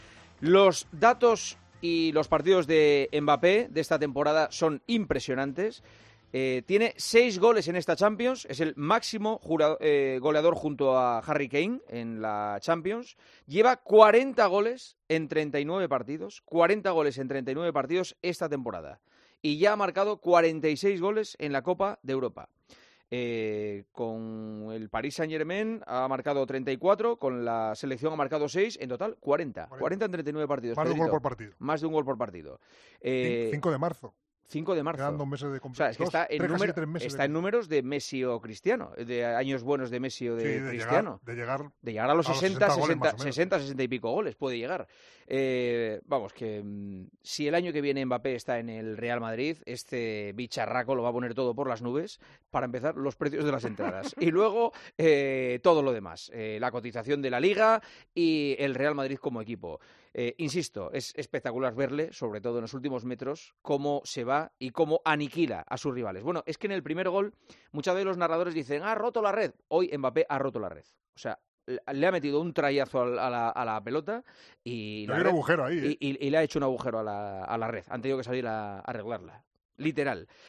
"Insisto, es espectacular verle, sobre todo en los últimos metros, como se va y como aniquila a sus rivales", destaca el presentador.